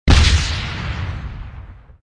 debri explo 4.wav